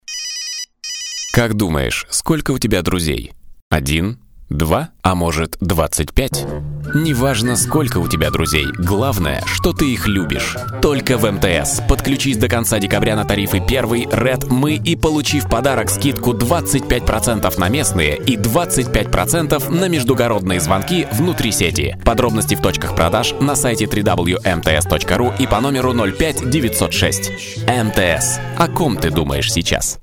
Ролик длительностью 30 секунд с 1 декабря транслируется на федеральных радиостанциях.